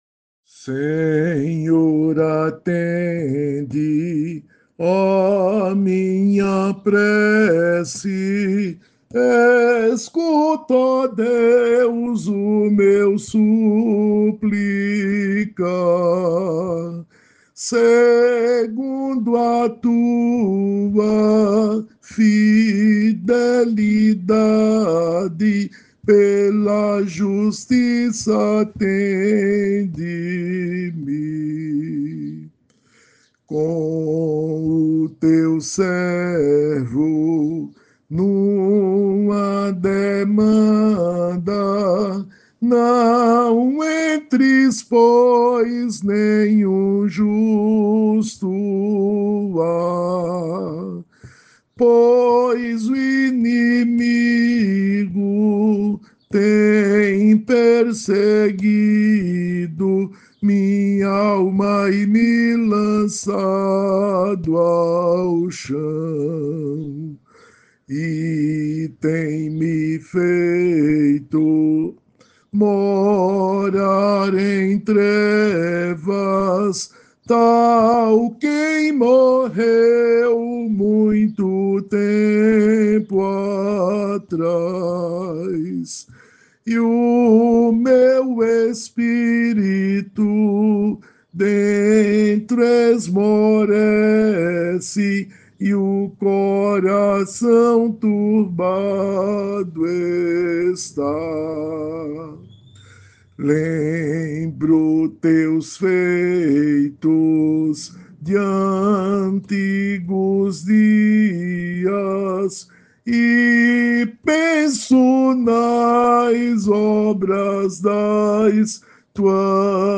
Salmo 143B Melodia: Schönster Herr Jesu Métrica: 5. 5. 9. 5. 5. 8 Melodia tradicional silesiana, 1842 Metrificação: Comissão Brasileira de Salmodia, 2014 1 1 Senhor, atende a minha prece.
salmo_143B_cantado.mp3